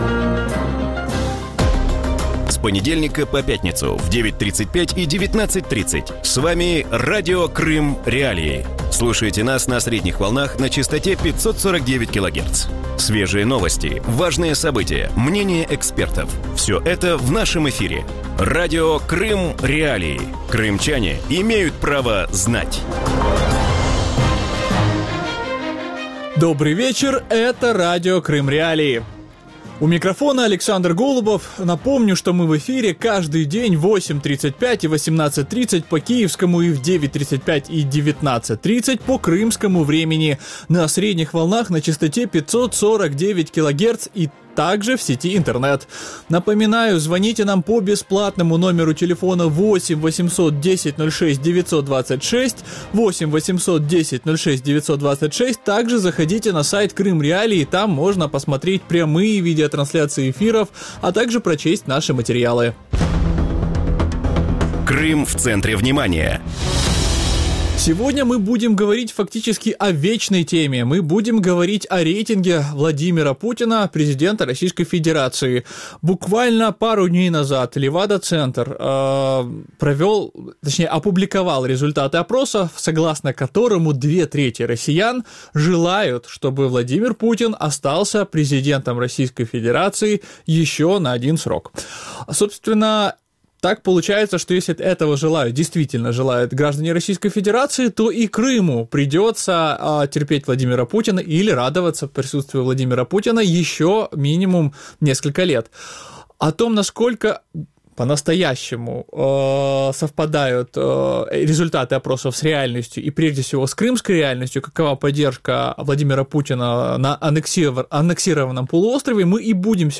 У вечірньому ефірі Радіо Крим.Реалії говорять про популярність російського президента Володимира Путіна і точності соціологічних опитувань у Росії. Чому, незважаючи на зниження якості життя, соціальні опитування показують зростаючий рейтинг Володимира Путіна і чому в країні не довіряють опозиції?